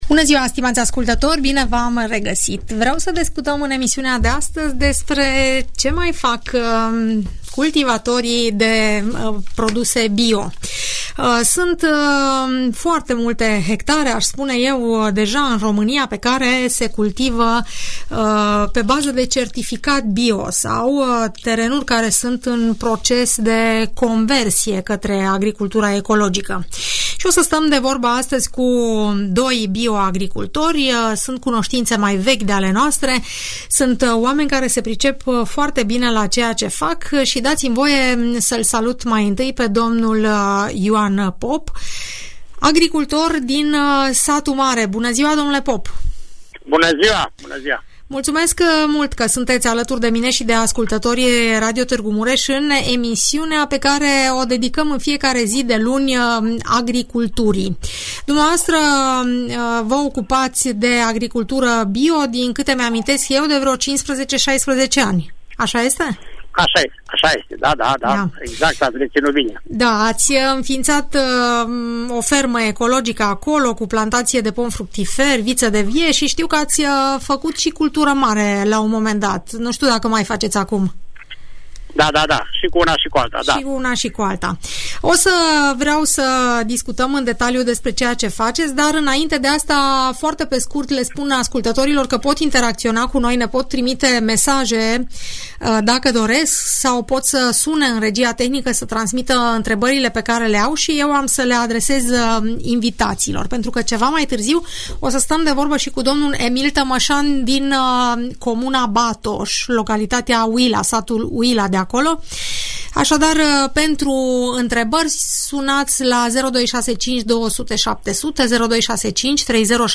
În această perioadă fermierii au foarte mult de lucru în livadă și în plantațiile viticole. Doi pomicultori cu experiență